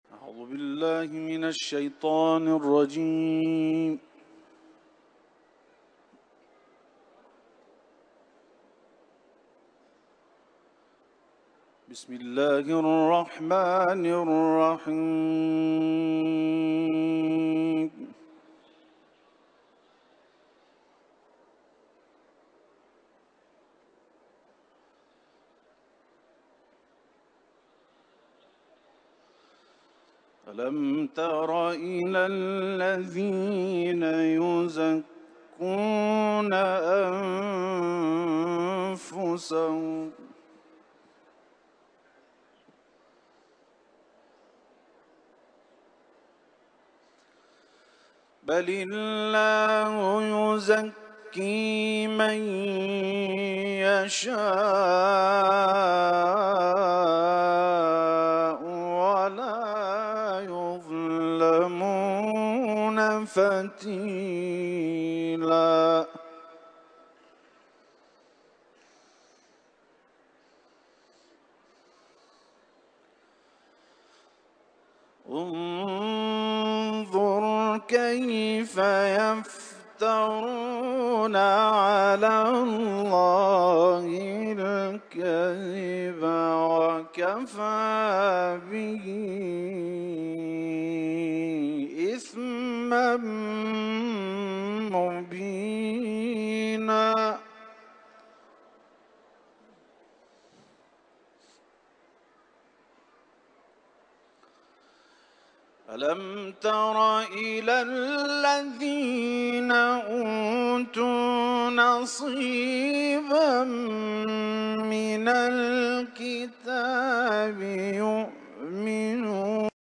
برچسب ها: تلاوت قرآن ، سوره نساء